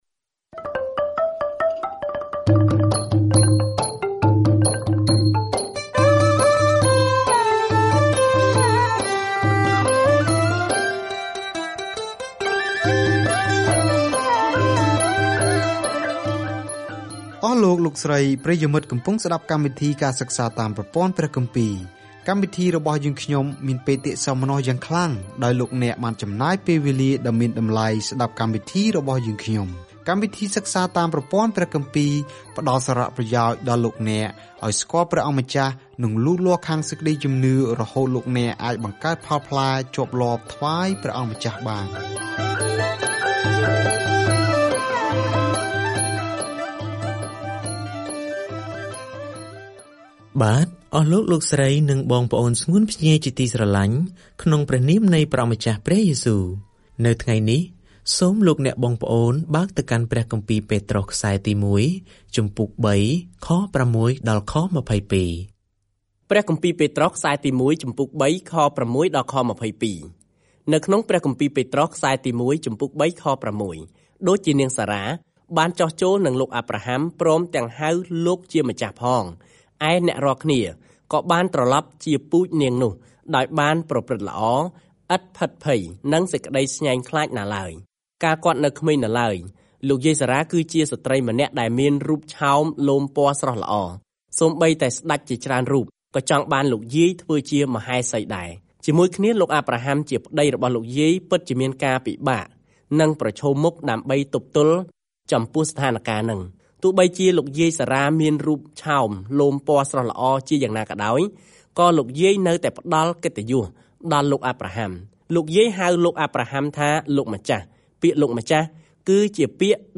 ប្រសិនបើអ្នករងទុក្ខជំនួសព្រះយេស៊ូវ នោះសំបុត្រទីមួយពីពេត្រុសលើកទឹកចិត្ដអ្នកថា អ្នកកំពុងដើរតាមគន្លងរបស់ព្រះយេស៊ូវ ដែលបានរងទុក្ខជំនួសយើងមុន។ ការធ្វើដំណើរប្រចាំថ្ងៃតាមរយៈ ពេត្រុស ទី១ នៅពេលអ្នកស្តាប់ការសិក្សាអូឌីយ៉ូ ហើយអានខគម្ពីរដែលជ្រើសរើសចេញពីព្រះបន្ទូលរបស់ព្រះ។